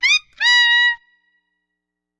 Звуки о, оу
Писклявый звук